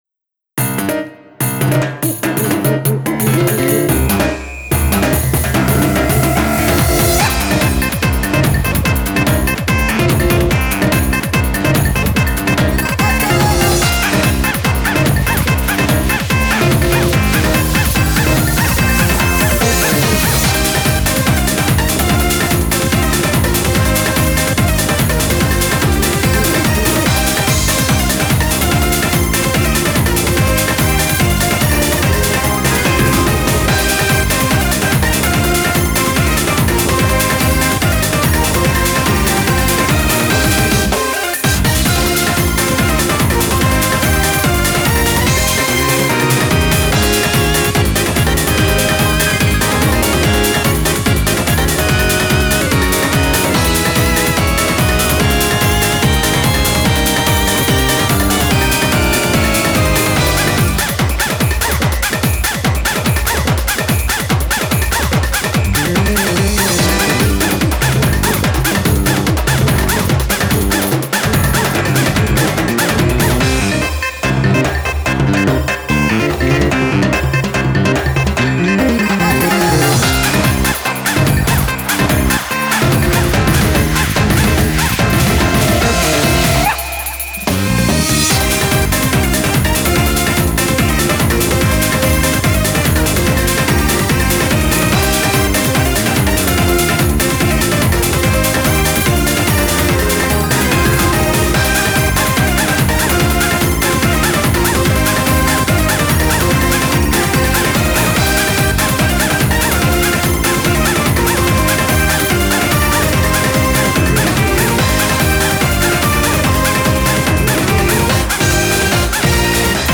BPM145
Audio QualityPerfect (High Quality)
GENRE: AFRO-SAMBA